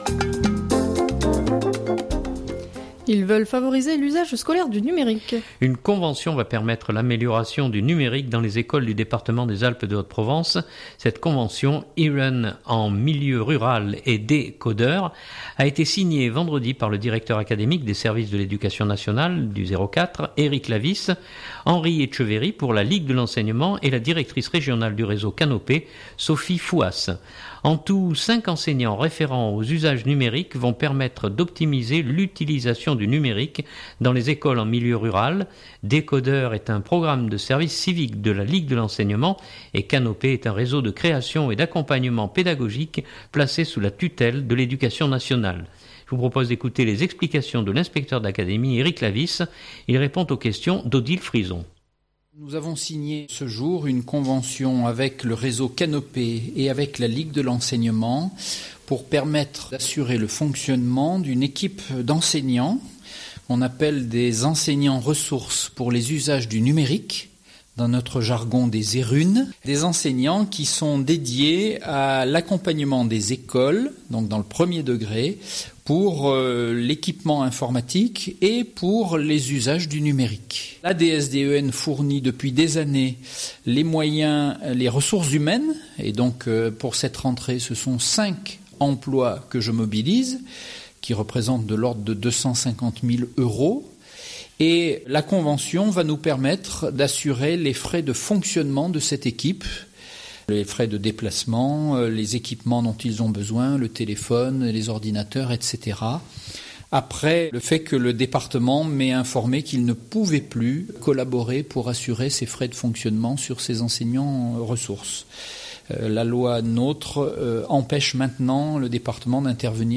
Je vous propose d’écouter les explications de l’inspecteur d’académie Eric Lavis.